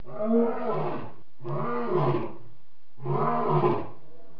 دانلود آهنگ حیوانات جنگلی 104 از افکت صوتی انسان و موجودات زنده
دانلود صدای حیوانات جنگلی 104 از ساعد نیوز با لینک مستقیم و کیفیت بالا
جلوه های صوتی